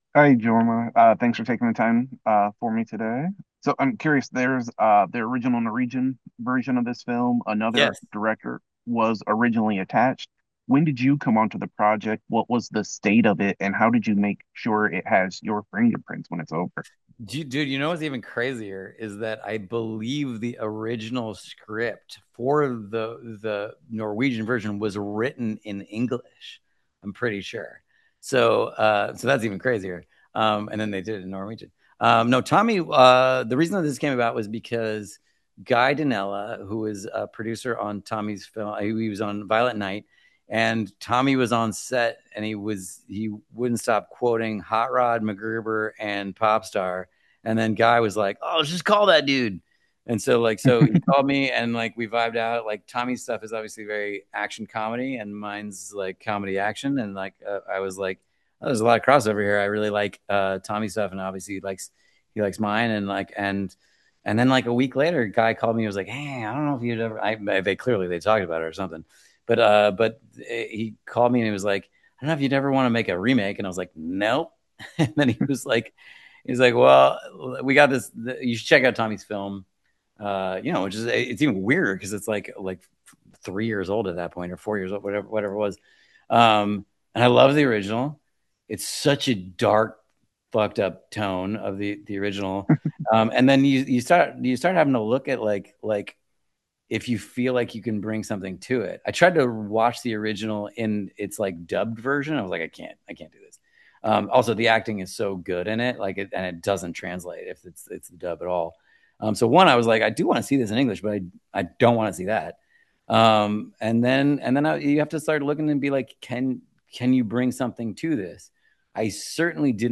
Movie Review: ‘Over Your Dead Body’ And Interview With Director Jorma Taccone
Interview: